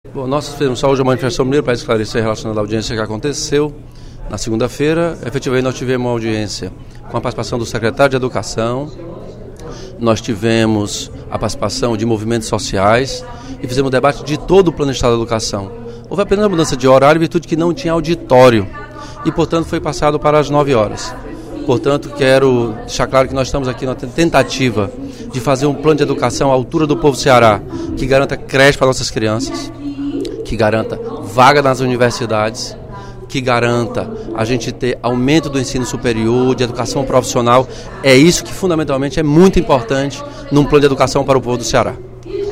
O deputado Elmano Freitas (PT) esclareceu, durante o primeiro expediente da sessão plenária desta quarta-feira (23/03), que a alteração de horário da audiência pública realizada na segunda-feira (21/03), para debater o Plano Estadual de Educação (PEE), “não foi proposital”.